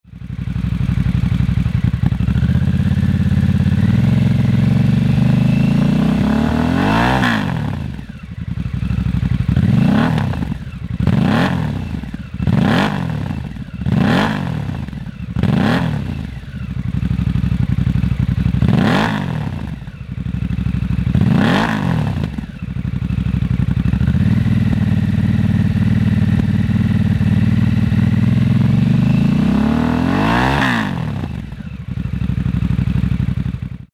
The S&S Grand National slip-on for the Nightster compliments that styling while letting the modern engine breathe while adding a much needed exhaust note
Removeable dB reducer (sound level tunable) (J2825 compliant).Equipped with O2 Lambda sensor plug housing
S&S Cycle - 4-1/2" Grand National Slip-On Muffler - Nightster - With dB Reducer